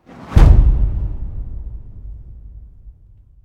cinematic-boom-171285.ogg